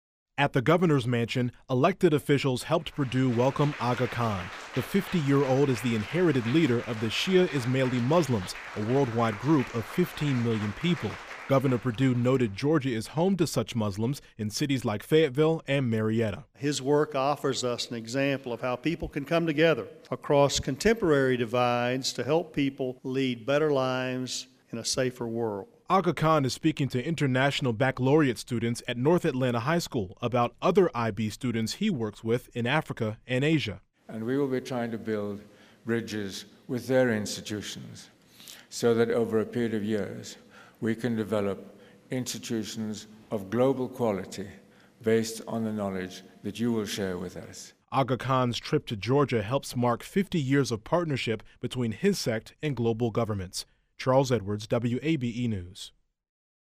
The Aga Khan is speaking at an Atlanta high school as part of a US tour.